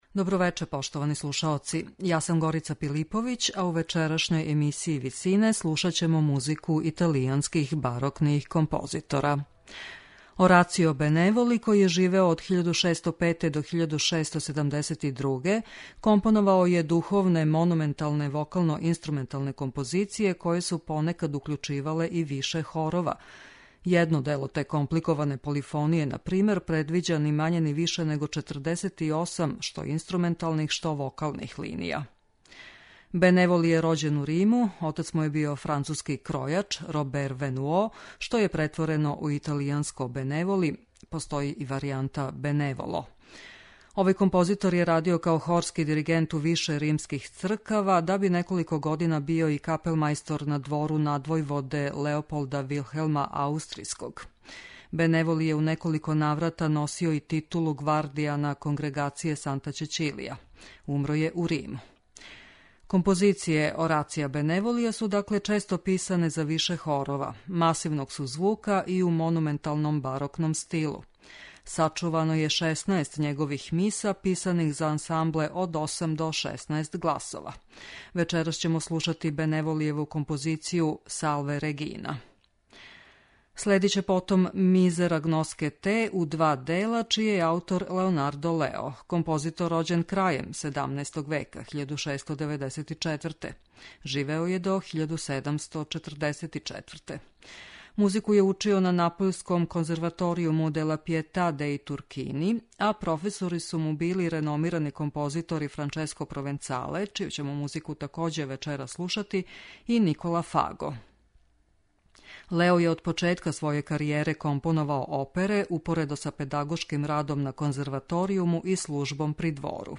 духовне композиције италијанских барокних аутора